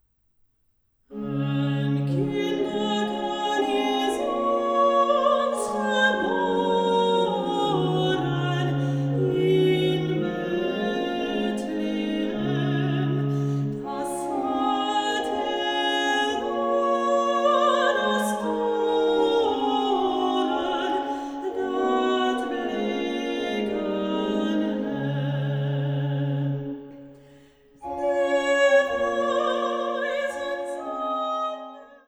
orgue flamand (Cattiaux) de l’église de Beurnevésin